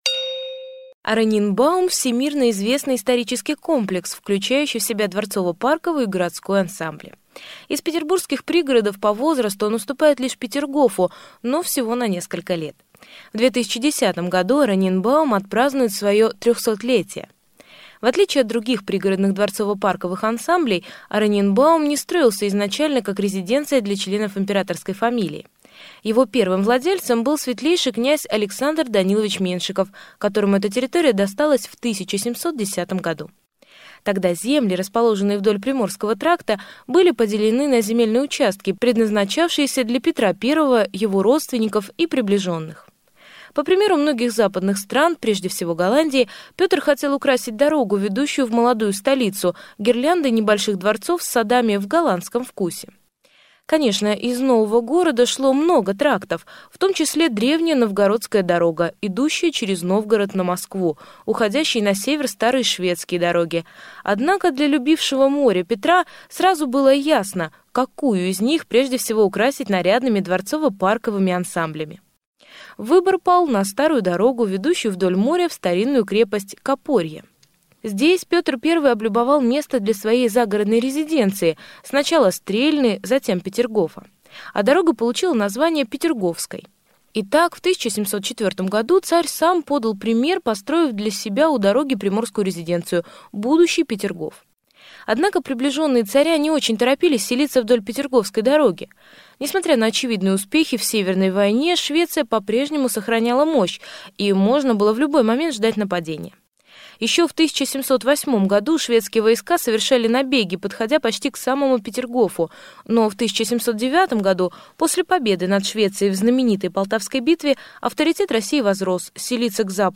Aудиокнига Ораниенбаум Автор М. Несин